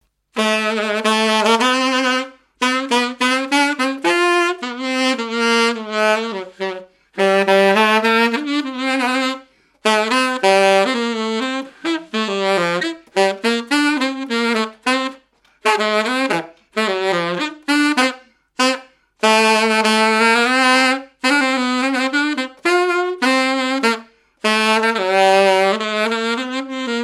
Mémoires et Patrimoines vivants - RaddO est une base de données d'archives iconographiques et sonores.
danse : quadrille : pastourelle
activités et répertoire d'un musicien de noces et de bals
Pièce musicale inédite